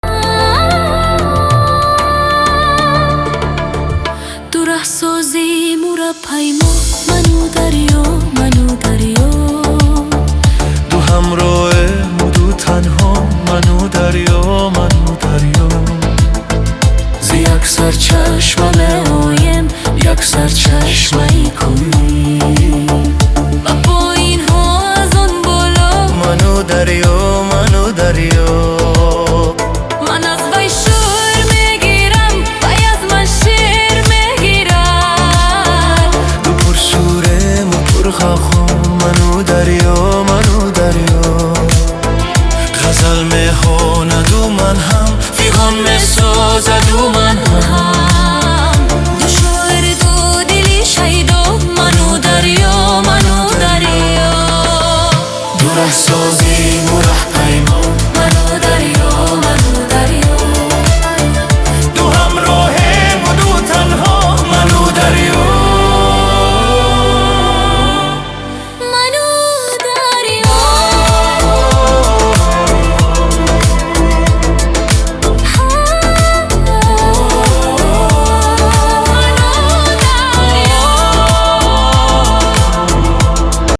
Качество: 320 kbps, stereo
Нейросеть Песни 2025
кавер